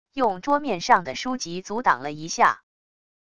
用桌面上的书籍阻挡了一下wav音频